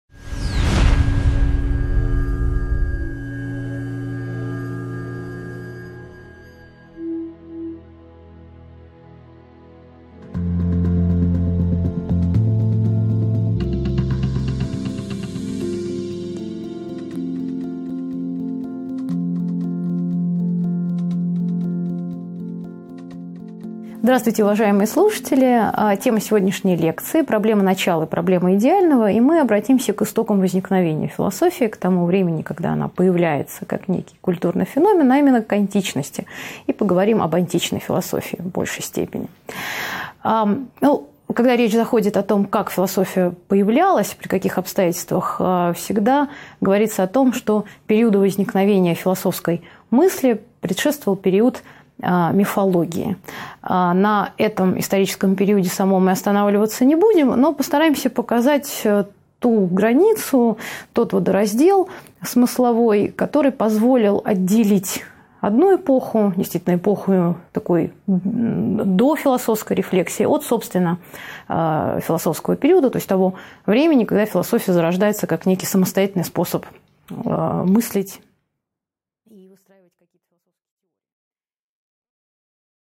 Аудиокнига 2.1 Античность. Мудрецы и философы | Библиотека аудиокниг